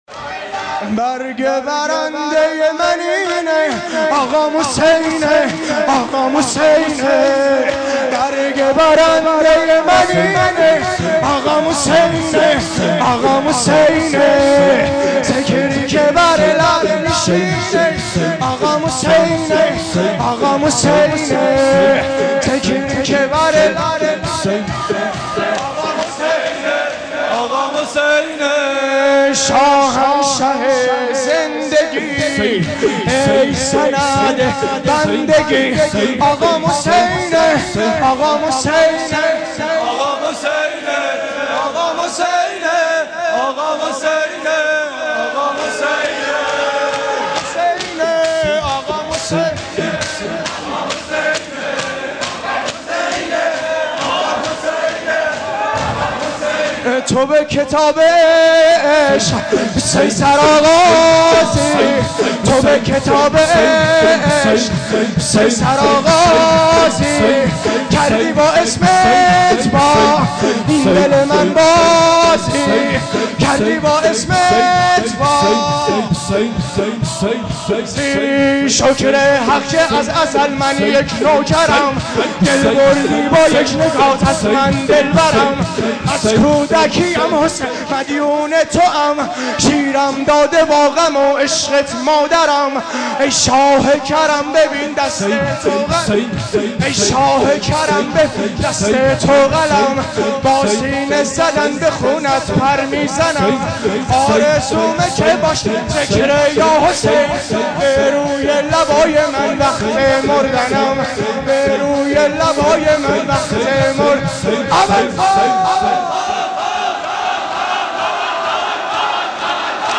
Madahi-04.mp3